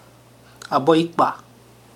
[ LHHL ] noun adolescent girl